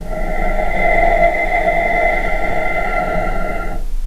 vc-F5-pp.AIF